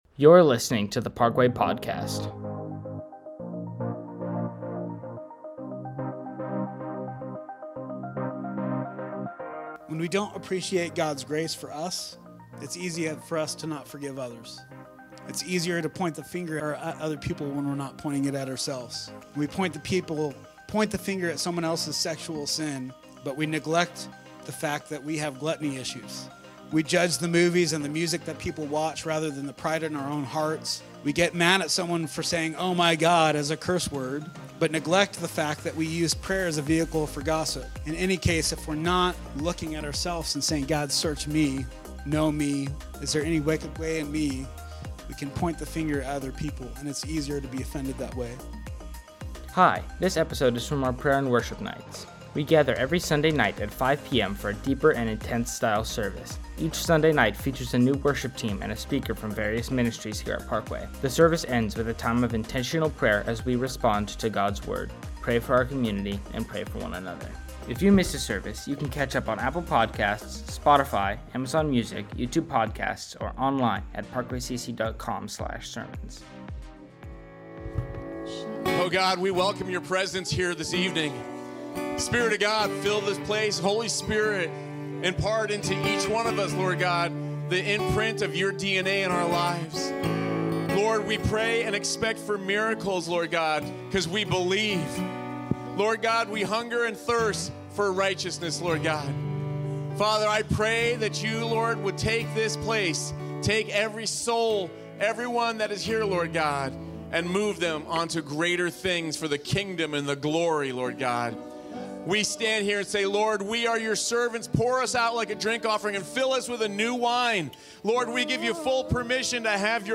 A message from the series "Prayer & Worship Nights."